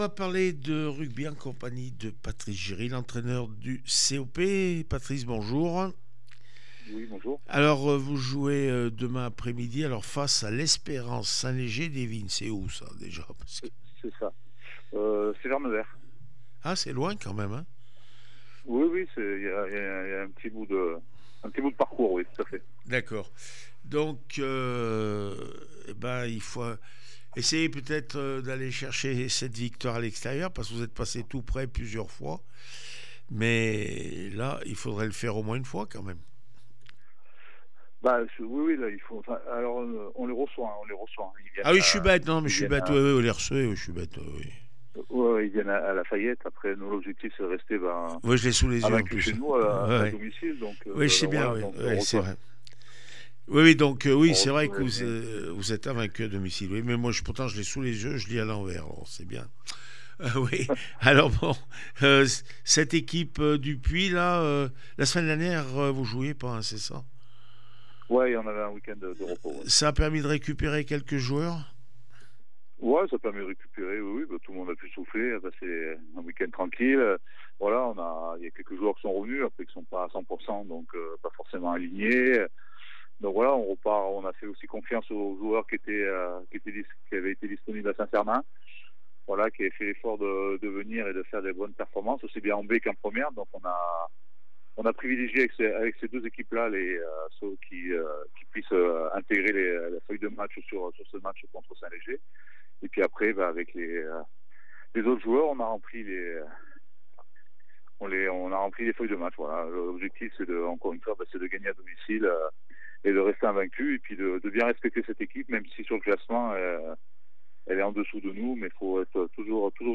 29 novembre 2025   1 - Sport, 1 - Vos interviews